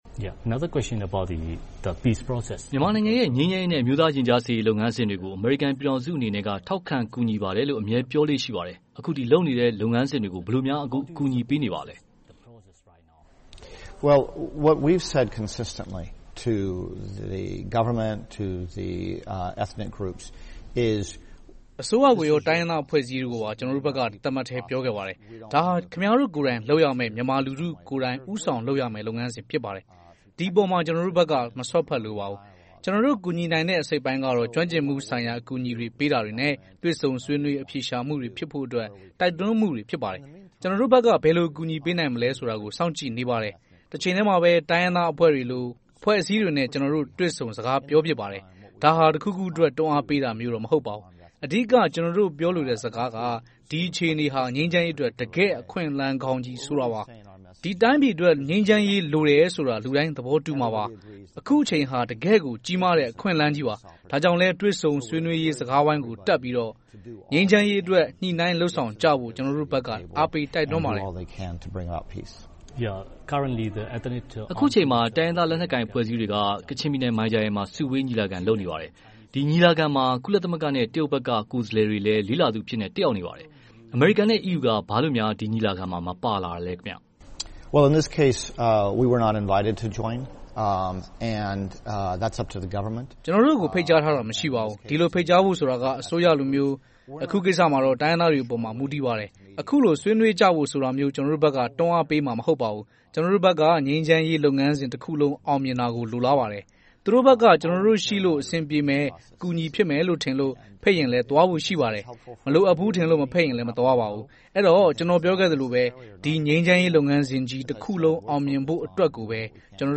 တွေ့ဆုံမေးမြန်းခန်း။